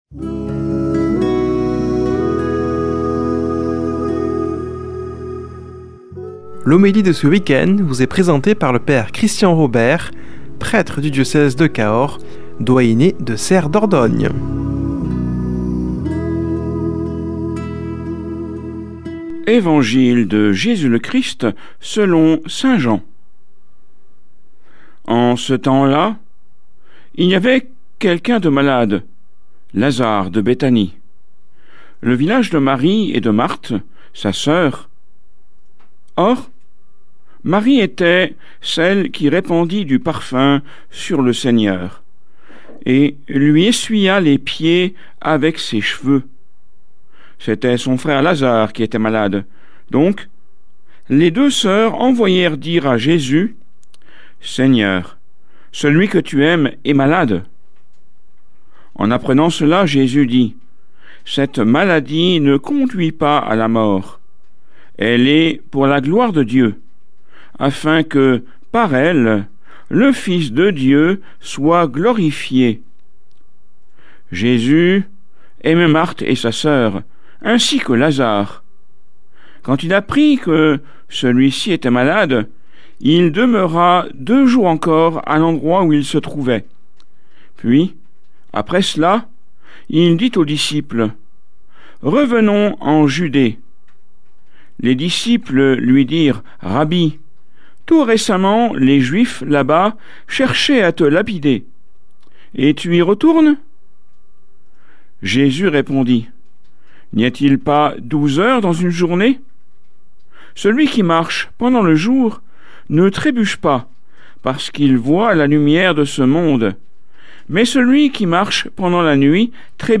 Homélie du 21 mars